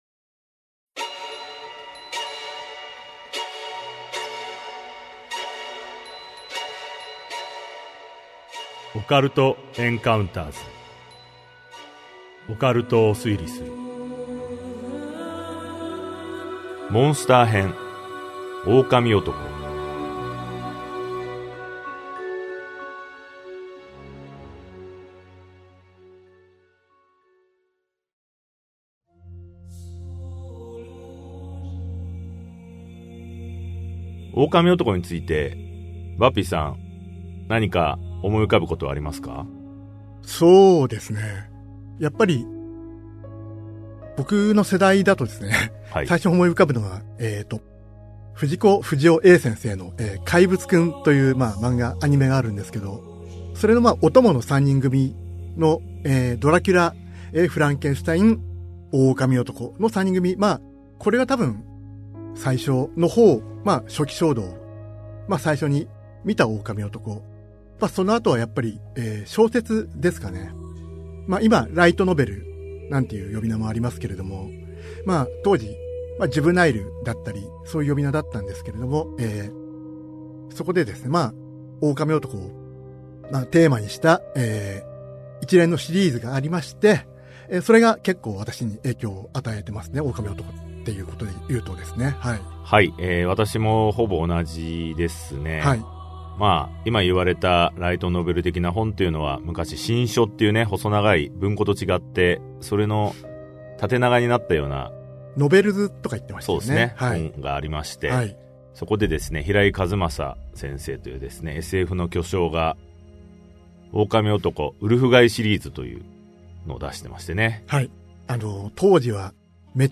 [オーディオブック] オカルト・エンカウンターズ オカルトを推理する Vol.13 狼男と半魚人